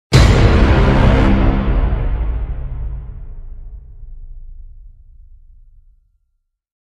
Inception Horn Sound Effect